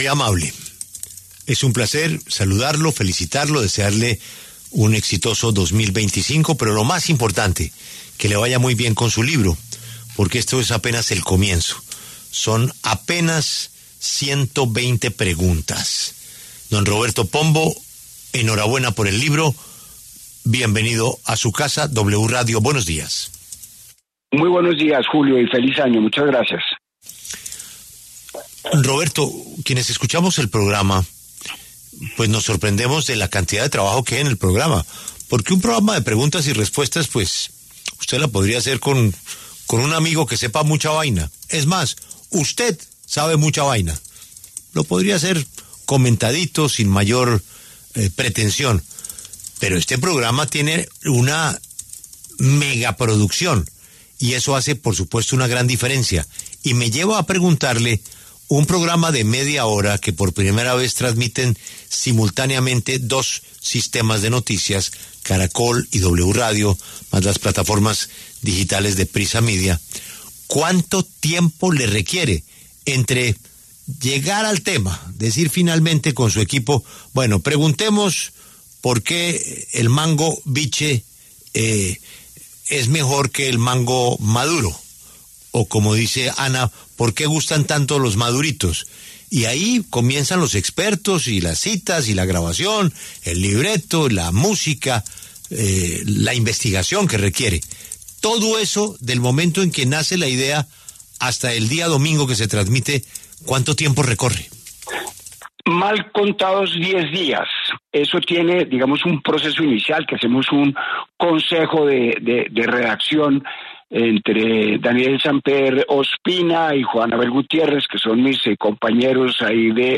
Pombo estuvo en los micrófonos de La W con Julio Sánchez Cristo y entregó detalles de este nuevo libro que ha sido muy buscado por miles de colombianos.